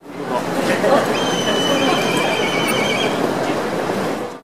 날카로운 휘파람 소리에 이어 빠른 울음소리가 특징이다.
울음소리